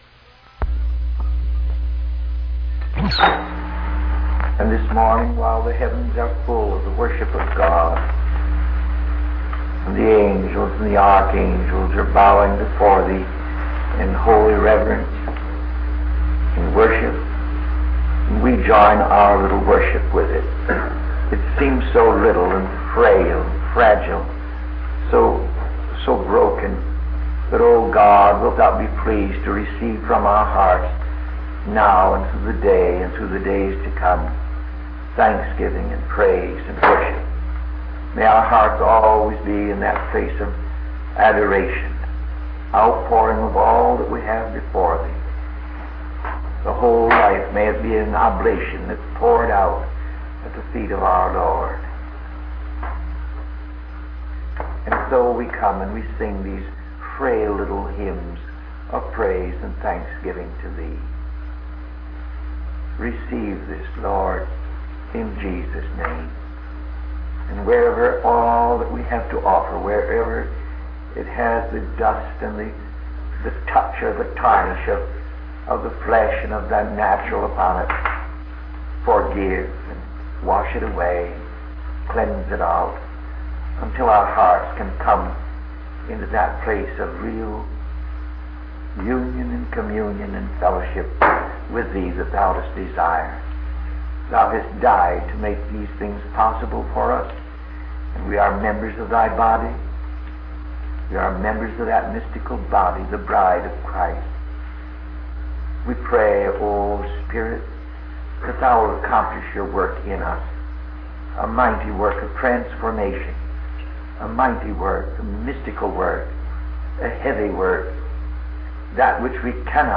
In this sermon, the preacher discusses the voice of the world and how it tries to appeal to us through various legitimate things in our lives.